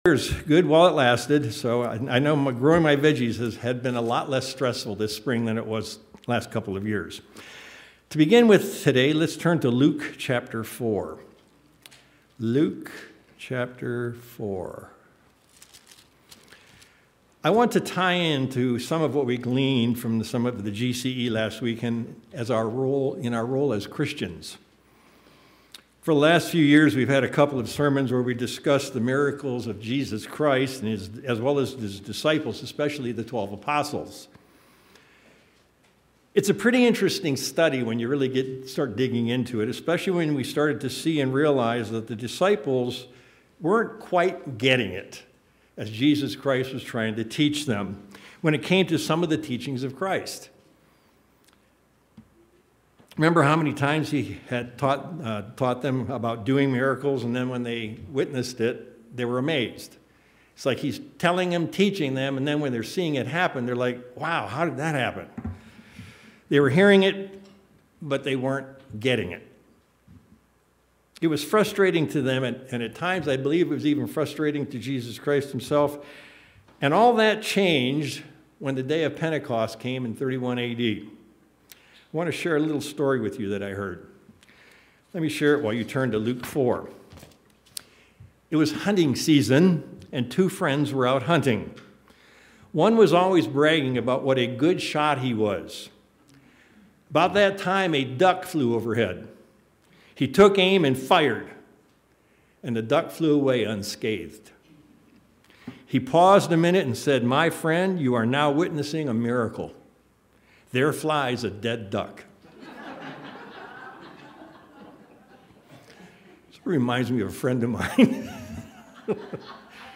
Given in El Paso, TX Tucson, AZ